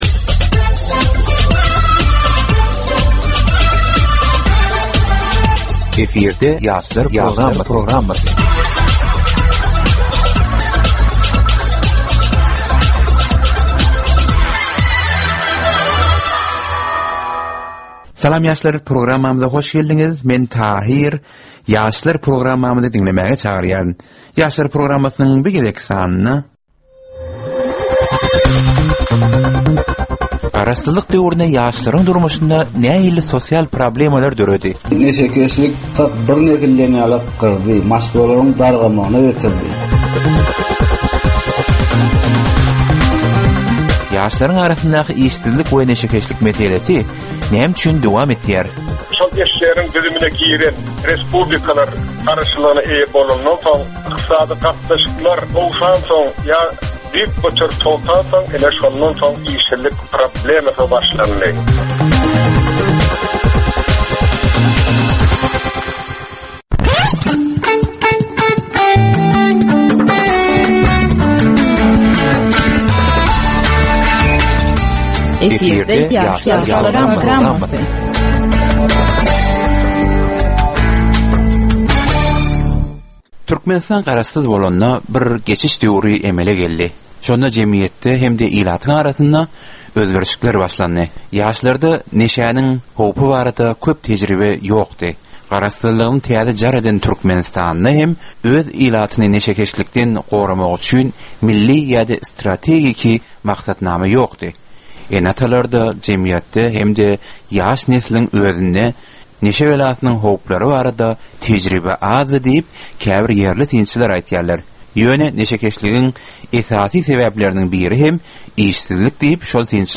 Türkmen we halkara yaşlarynyň durmuşyna degişli derwaýys meselelere we täzeliklere bagyşlanylyp taýýarlanylýan 15 minutlyk ýörite gepleşik. Bu gepleşikde ýaşlaryn durmuşyna degişli dürli täzelikler we derwaýys meseleler barada maglumatlar, synlar, bu meseleler boýunça adaty ýaşlaryň, synçylaryň we bilermenleriň pikrileri, teklipleri we diskussiýalary berilýär. Gepleşigiň dowamynda aýdym-sazlar hem eşitdirilýär.